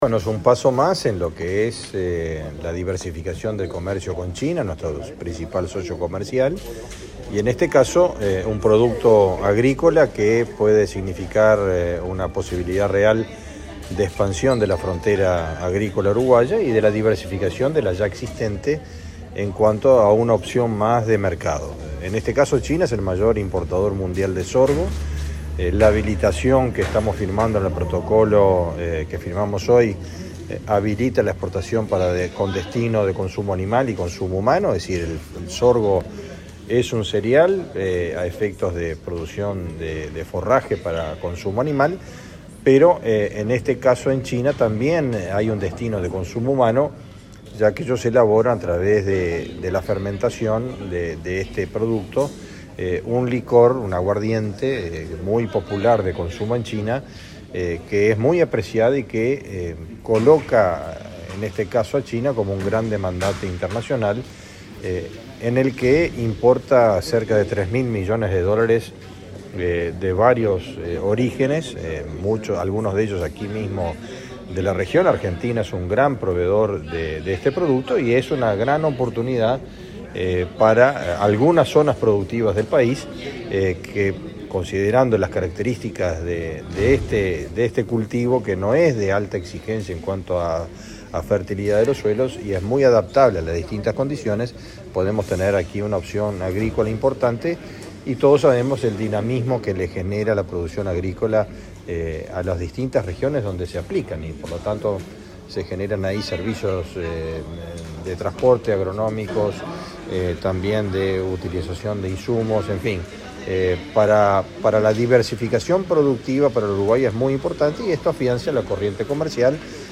Declaraciones del ministro de Ganadería, Fernando Mattos
Luego el secretario de Estado dialogó con la prensa.